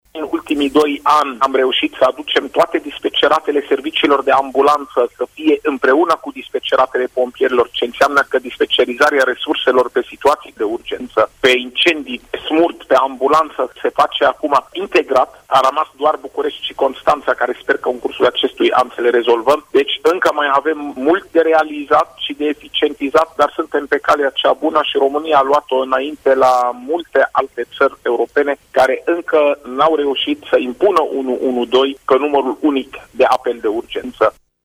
Invitat la RRA de Ziua europeană a numărului unic de urgenţă 112, doctorul Raed Arafat a prezentat planurile de dezvoltare a acestui serviciu: